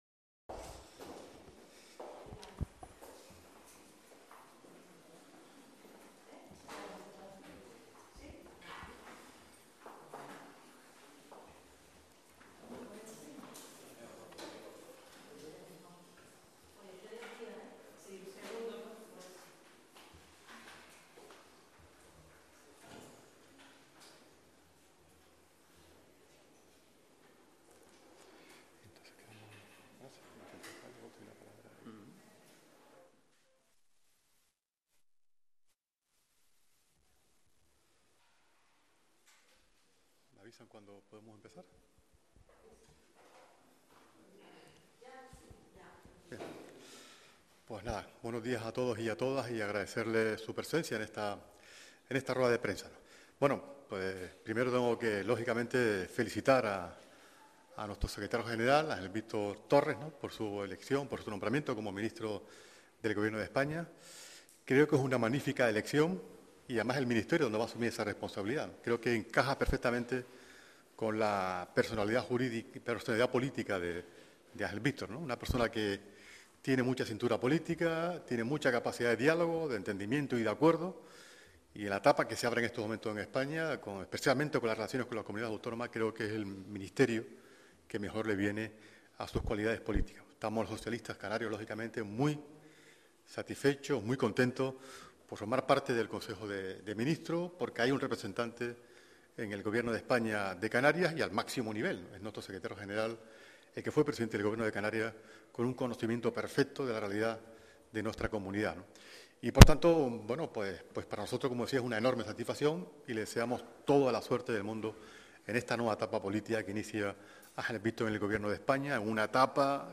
Rueda de prensa del GP Socialista Canario sobre presentación de la enmienda a la totalidad al proyecto de ley de presupuestos de Canarias 2024 - 11:00